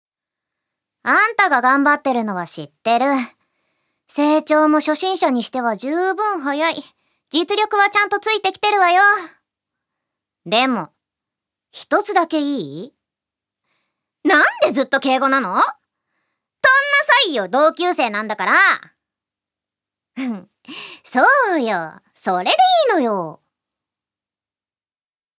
セリフ１